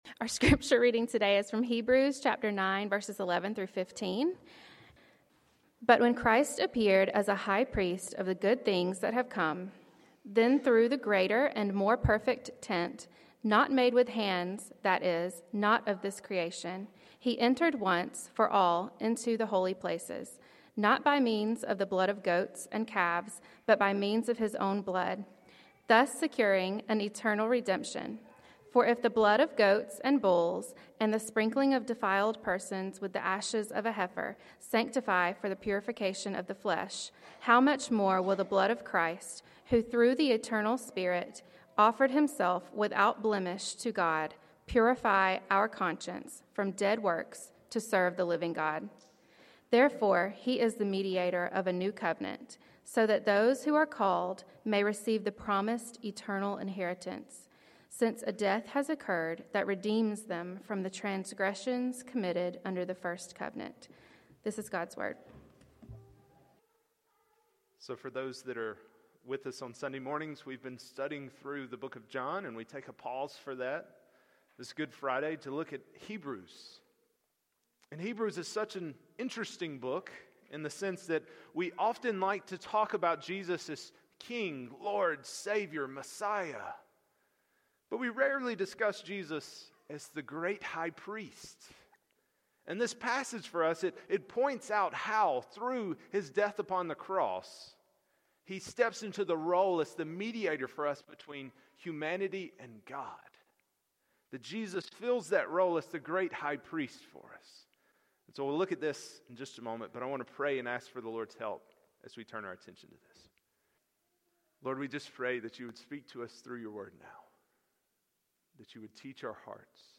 Passage: Hebrews 9:11-15 Sermon